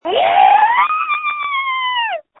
Screams from November 29, 2020
• When you call, we record you making sounds. Hopefully screaming.